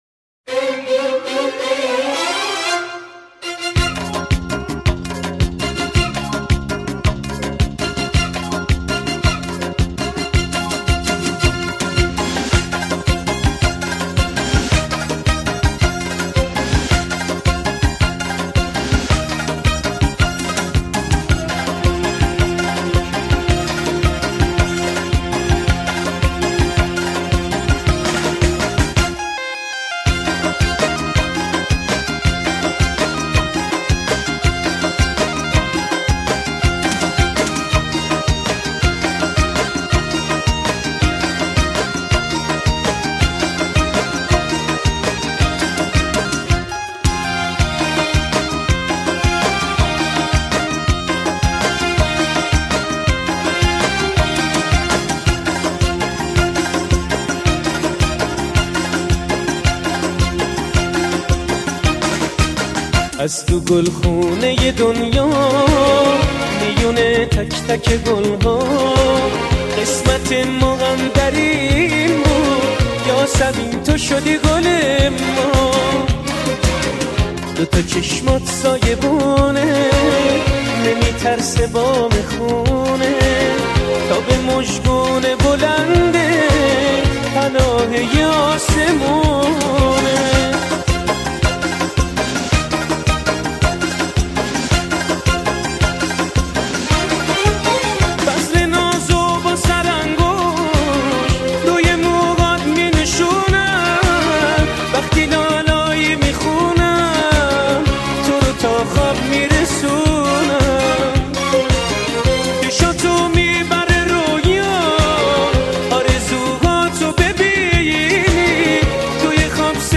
غمگین و احساسی